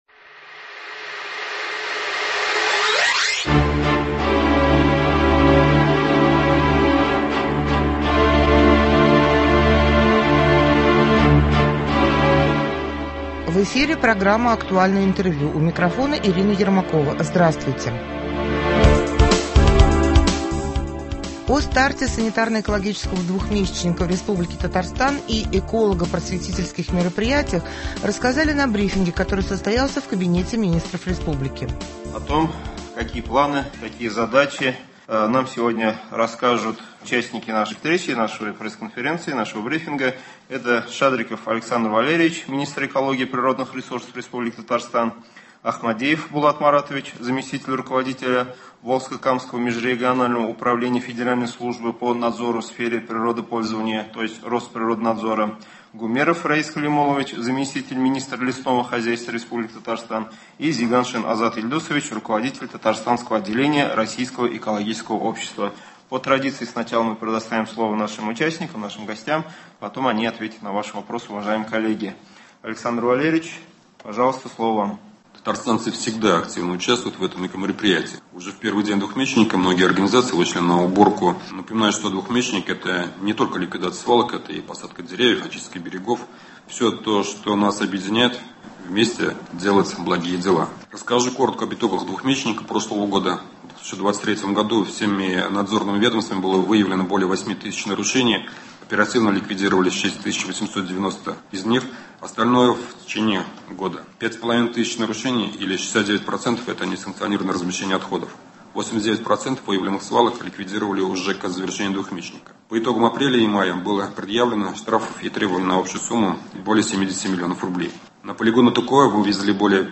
Актуальное интервью (03.04.24)